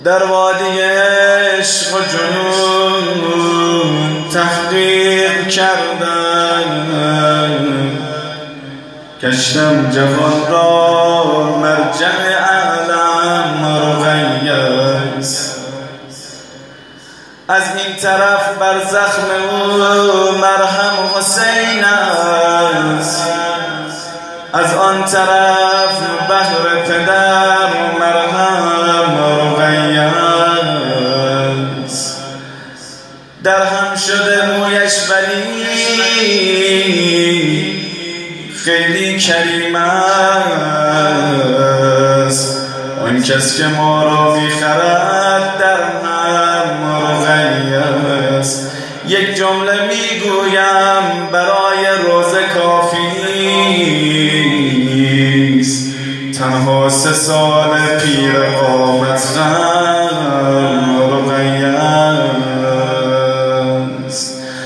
حضرت رقیه س - مدح